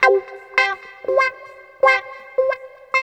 137 GTR 4 -L.wav